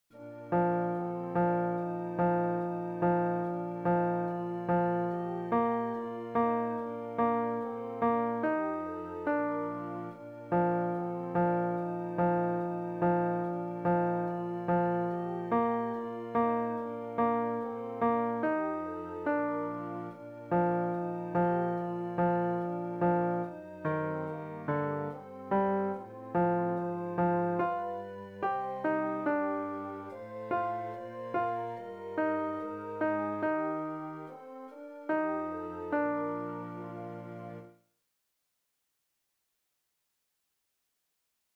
Chorproben MIDI-Files 508 midi files